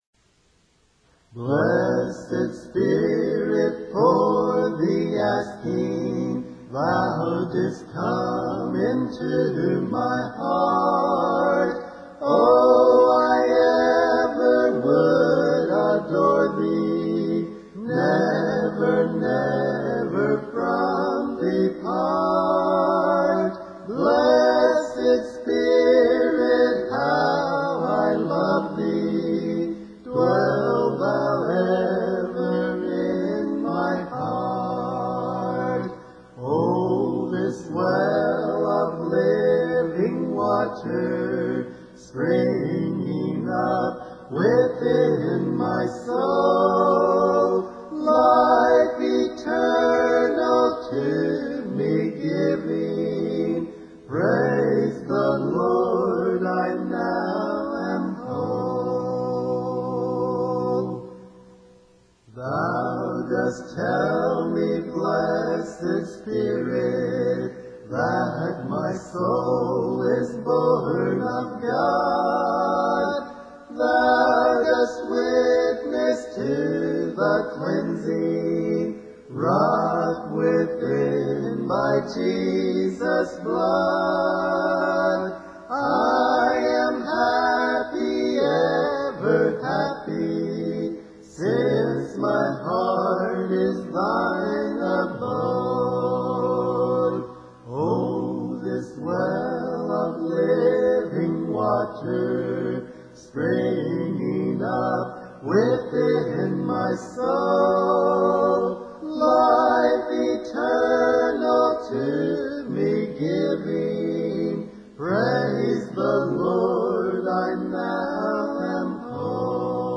Audio files: MIDI,
Key: B♭ Major